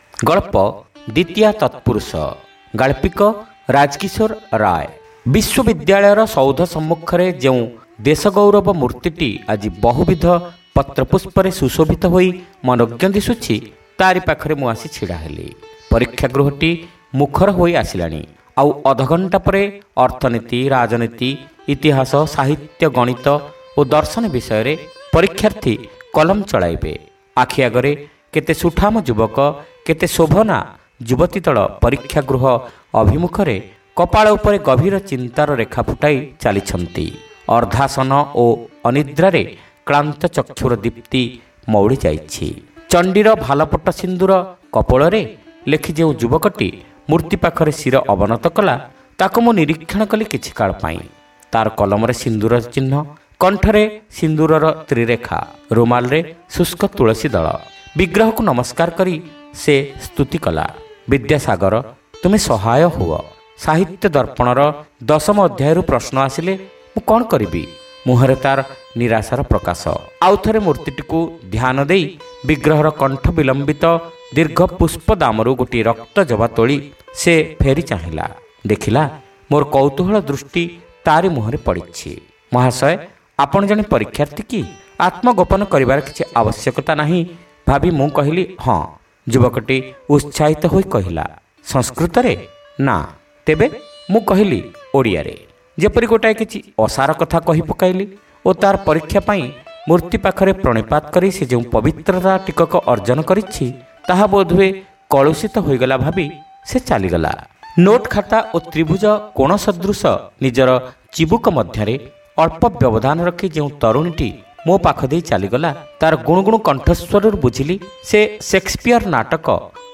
Audio Story : Dwitiya Tatpurusa